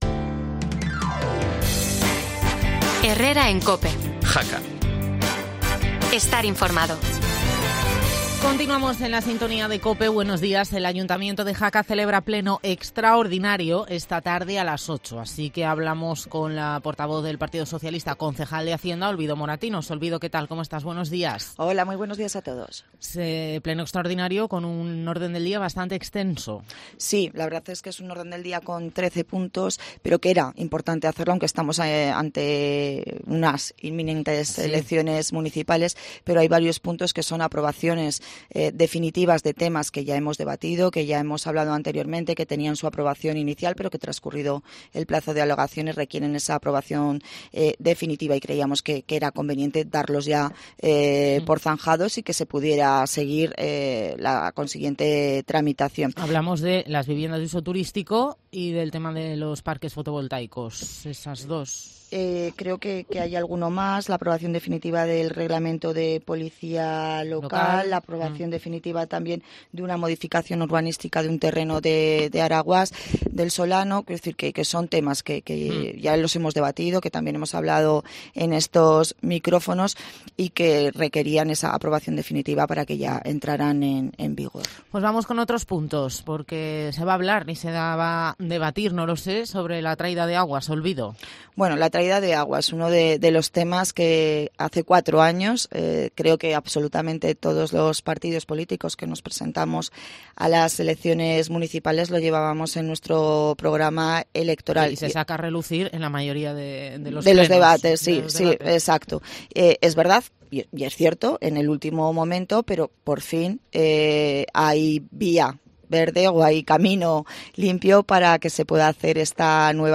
Olvido Moratinos, portavoz PSOE y concejal de Hacienda